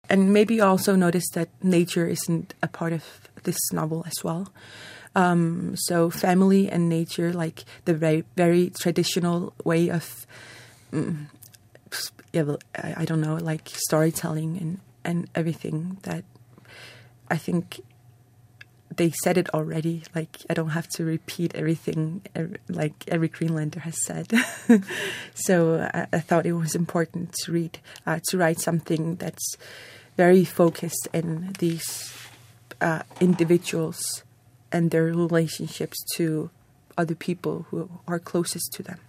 Feature Interview